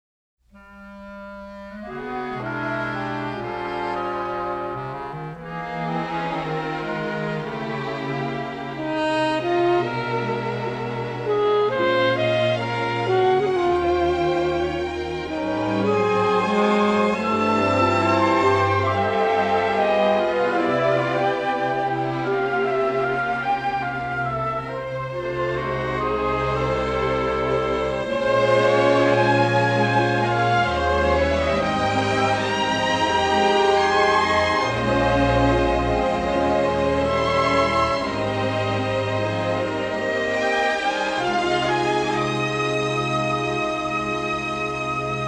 a deeply melodic, romantic and sophisticated score